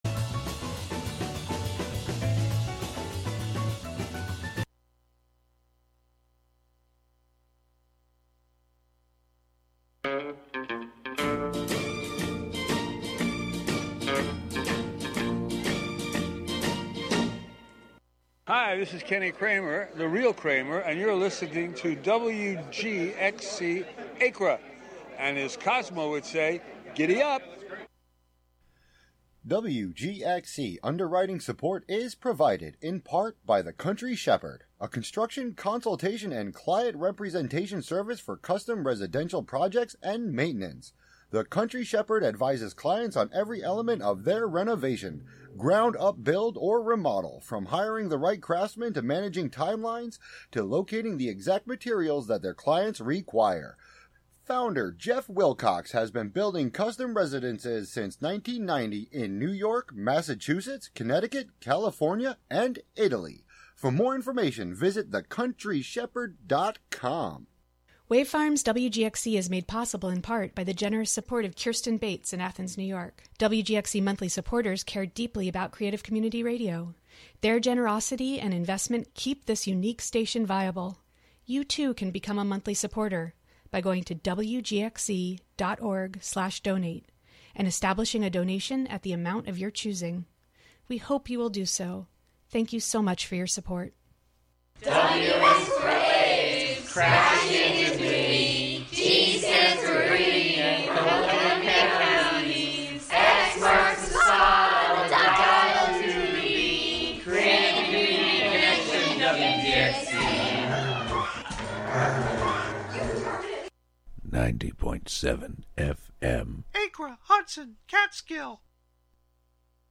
Monthly excursions into music, soundscape, audio document, and spoken word, inspired by the wide world of performance. Live from Ulster County.